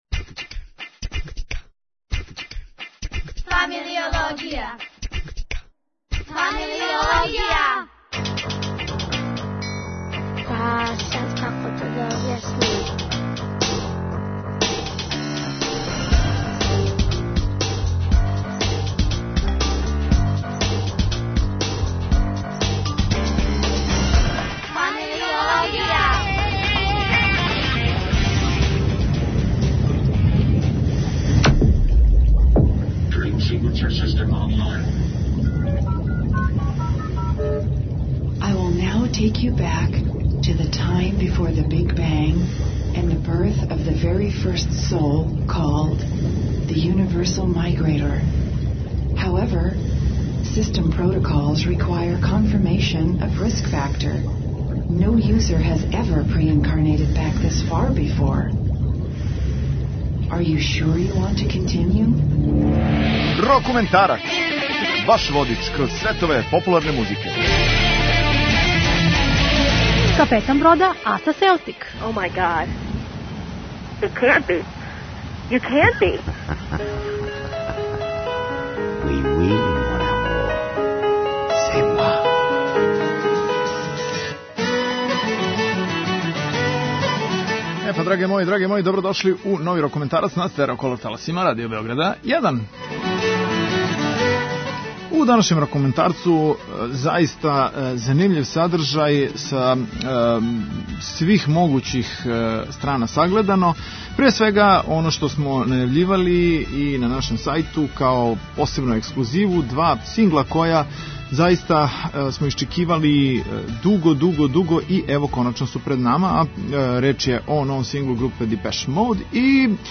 електро-поп
заразан и мејнстрим "radio friendly" сингл
И стварно враћају глам рок и прогресив звук на велика врата.